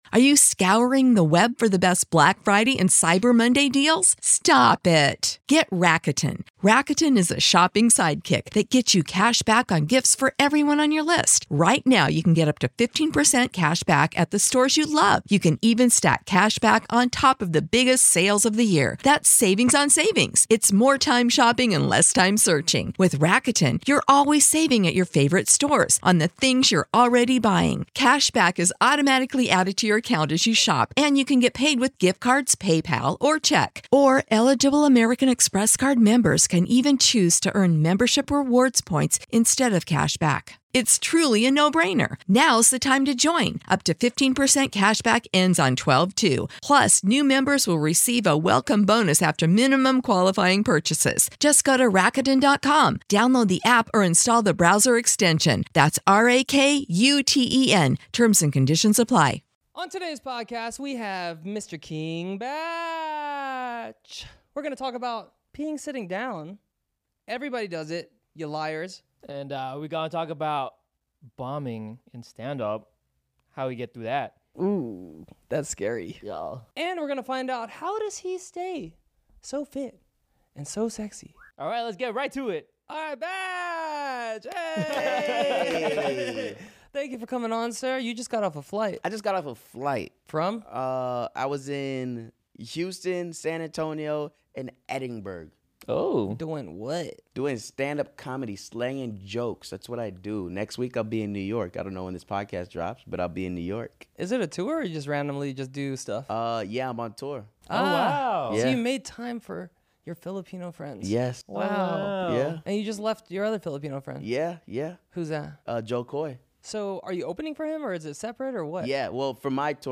King Bach has over 4 million YouTube Subscribers and you probably have seen him on countless viral videos on Vine, TikTok, and numerous Netflix Movies. Enjoy one of the most entertaining interviews we've ever had on the Spicy Fruit Podcast.